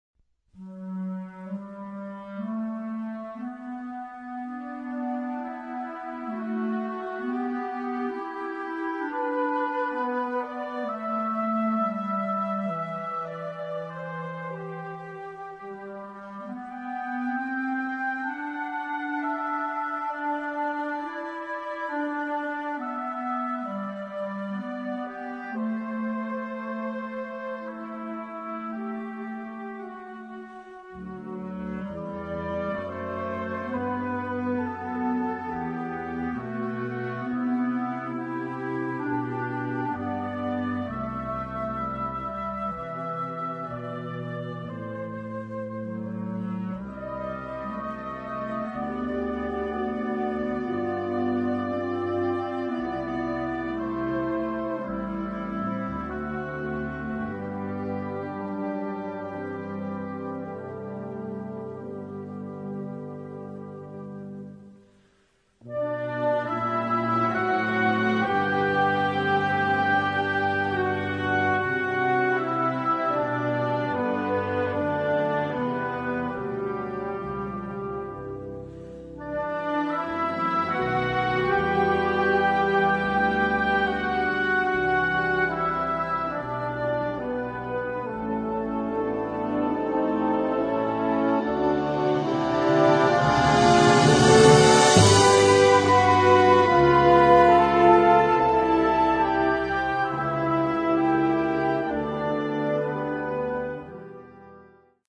concert band ballad
Partitions pour orchestre d'harmonie.